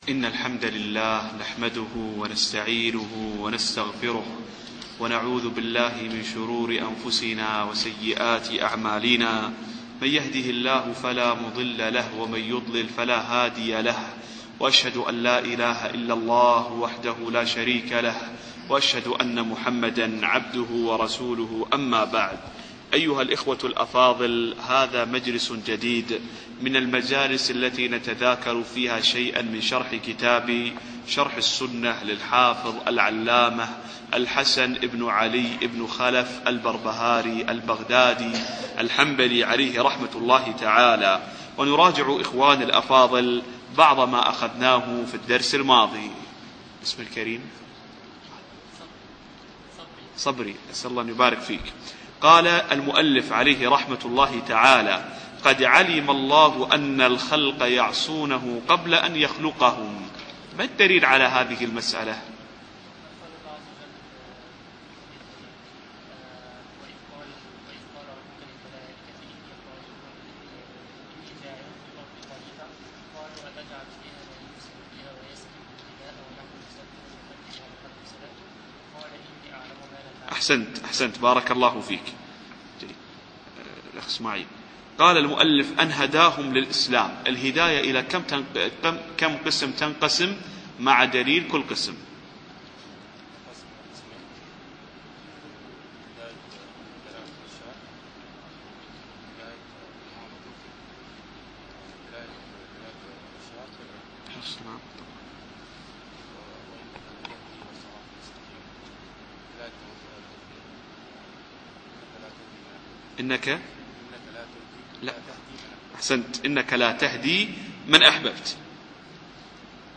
الدرس العشرون